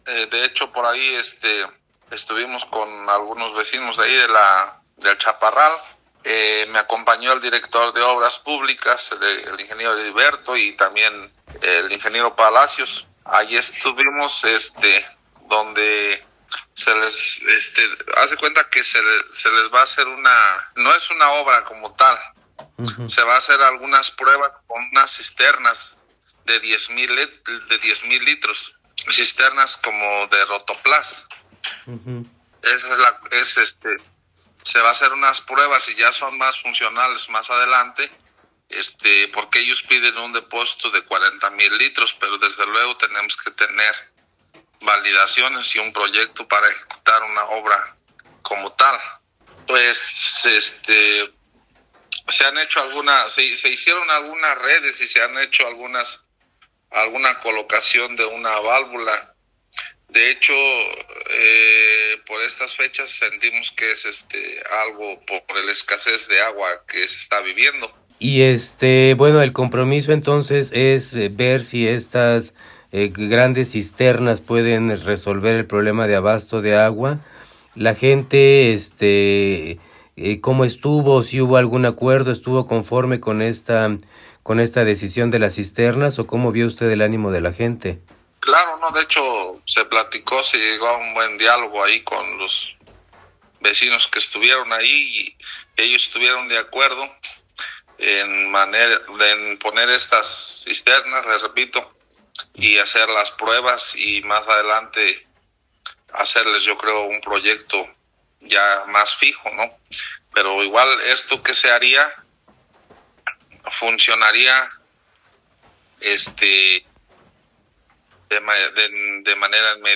Debido a que el presidente no se presentó ese día, el sábado los manifestantes se presentaron en su casa y para el día domingo Manuel Lemus presidente municipal acudió al barrio para platicar con las personas inconformes.